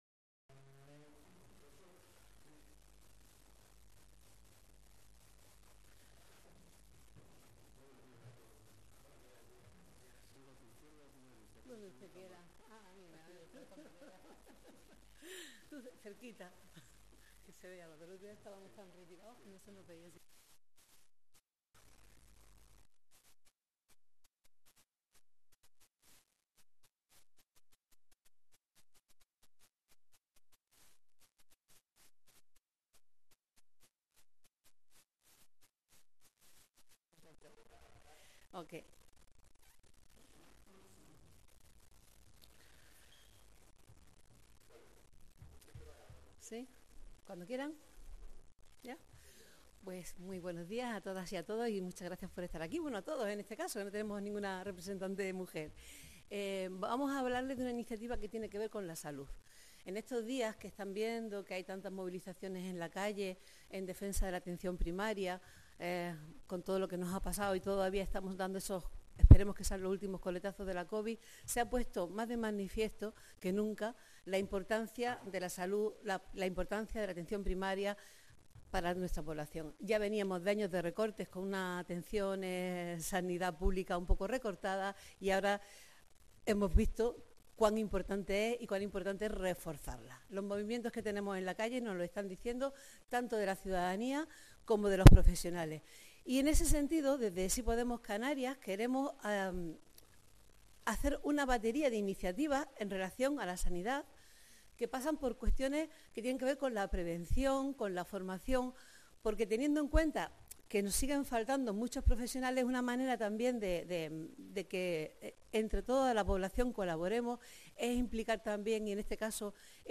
Rueda de Prensa sobre políticas referidas a los ámbitos educativo y sanitario de GP Sí Podemos Canarias.